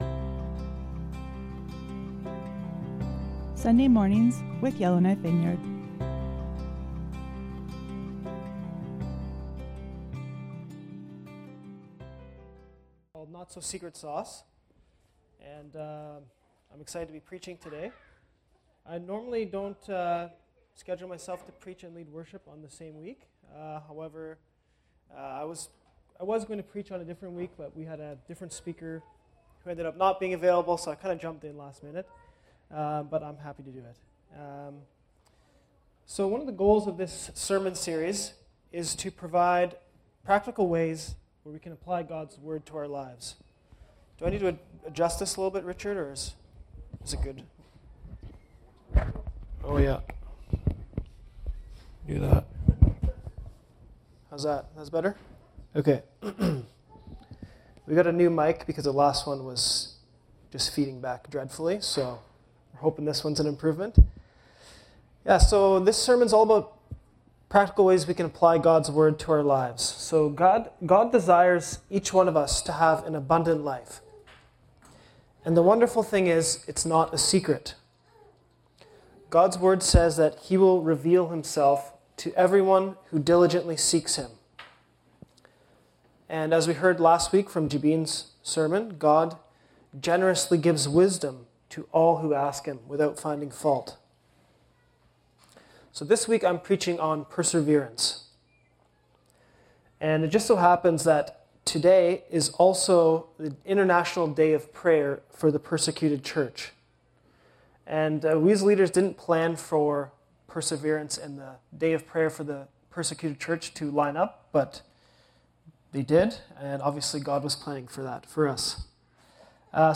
Sermons | Yellowknife Vineyard Christian Fellowship